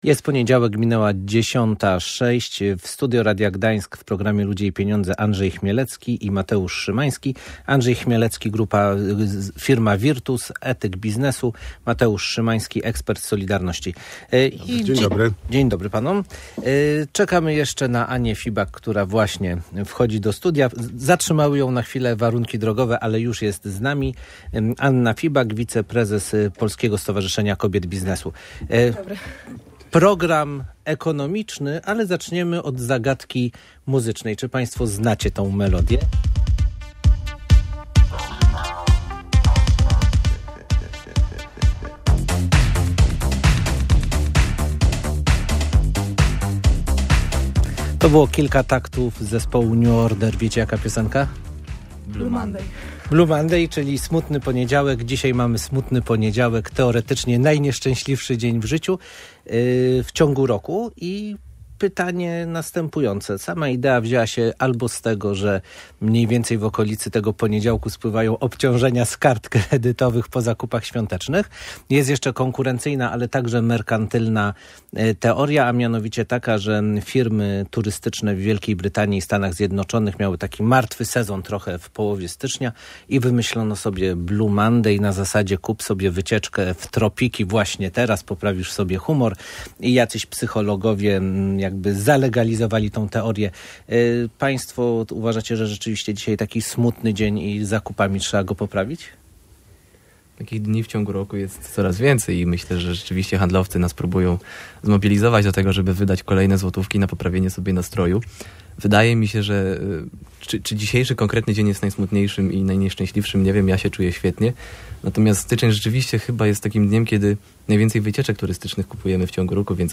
W audycji Ludzie i pieniądze w Radiu Gdańsk eksperci starali się wskazać, jakie drogi rozwoju mogą odwrócić proces podziału kraju na rejony o dużych dysproporcjach w rozwoju gospodarczym i społecznym.